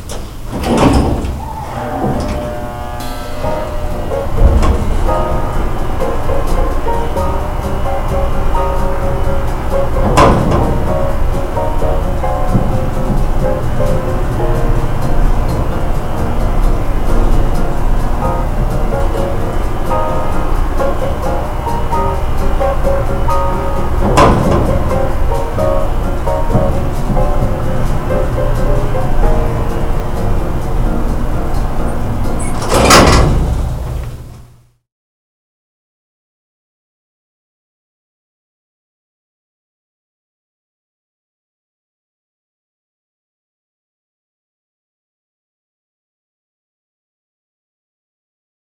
"Elevator Groove" provides a more relaxed audio environment.